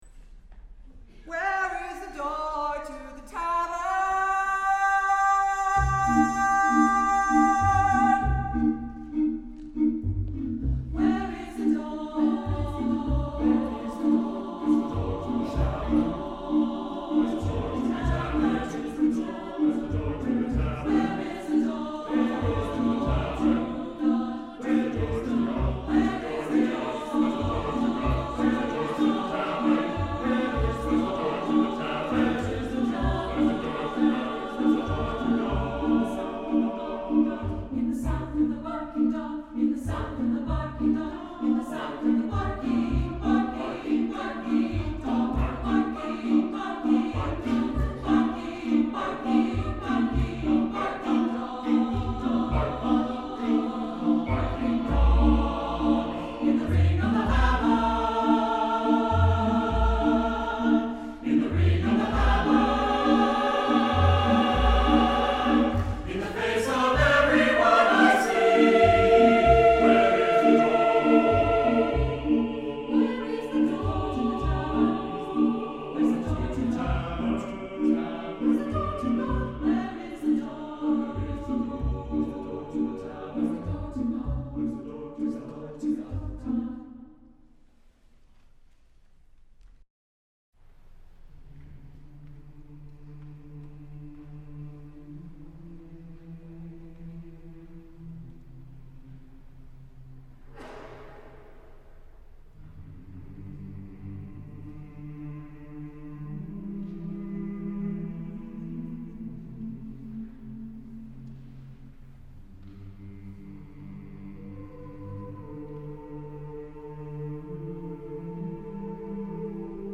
is a rowdy, polyphonic celebration
is playful, fun, highly rhythmic with soprano soli (2)